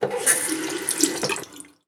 sink.wav